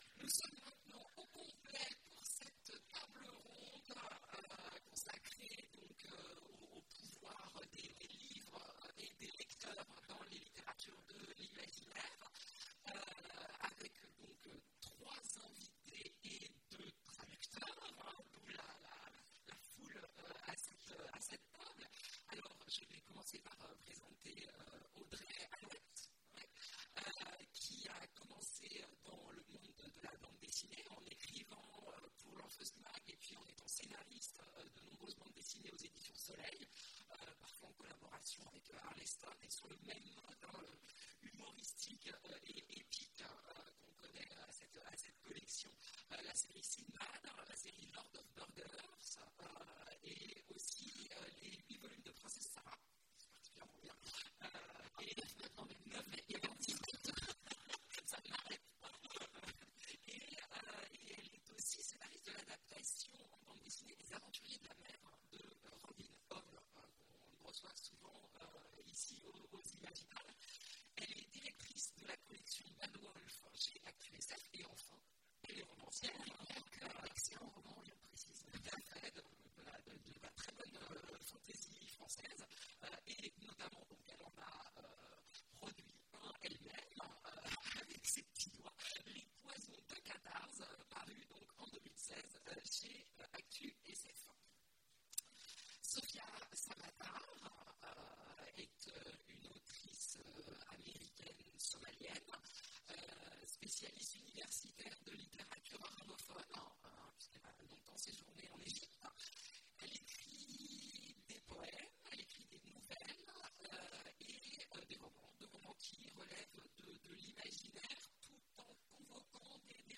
Mots-clés Conférence Partager cet article